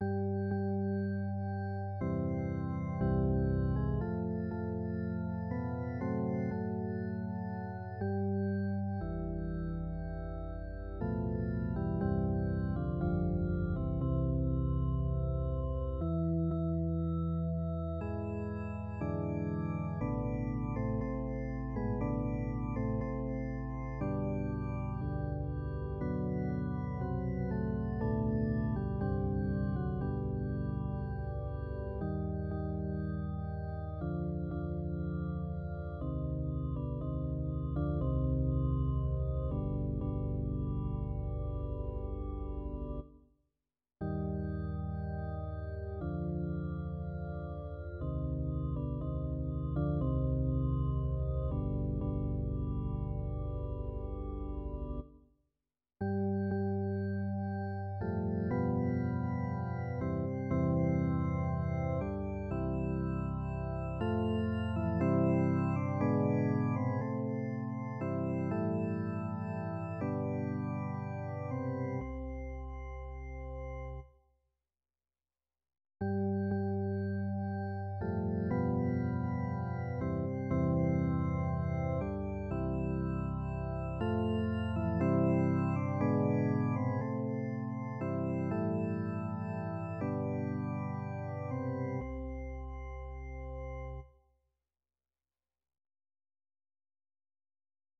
Vegyes karra